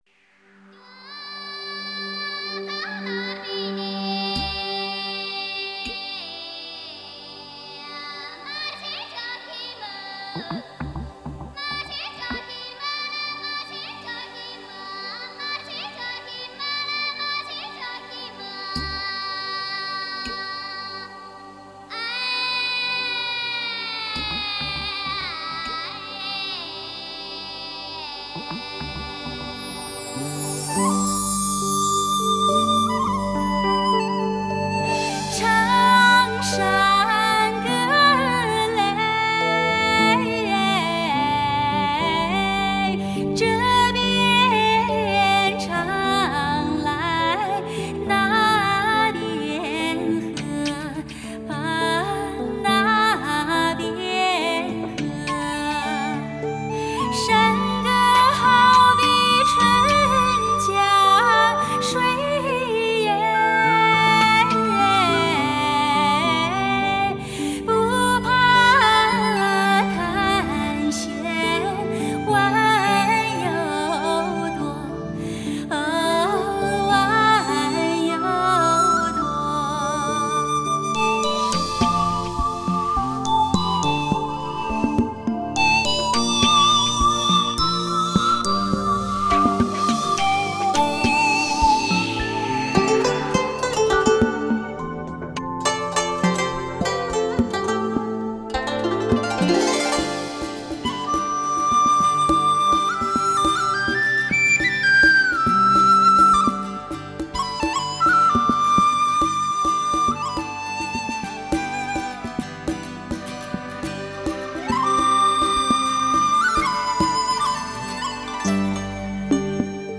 大量的真乐器的运用、各地采风的素材的歌曲，